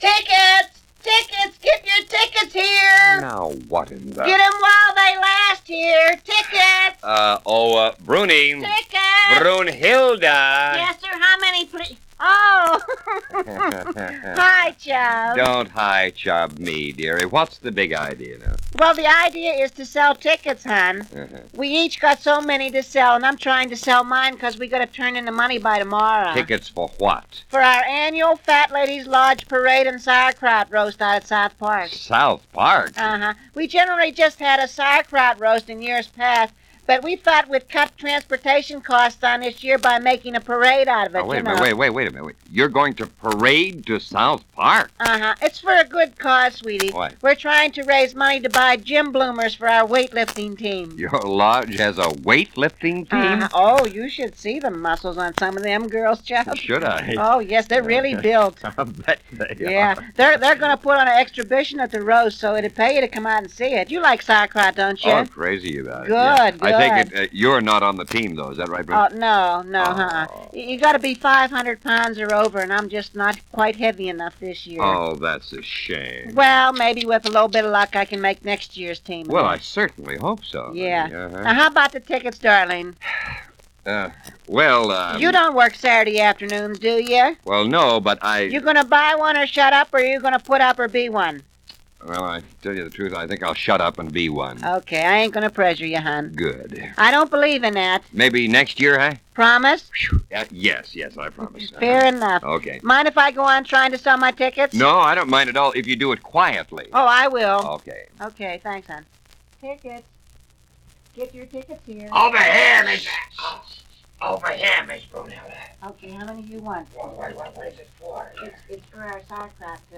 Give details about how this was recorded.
Archive 64-1 - The 1964 Studio LP's Part 1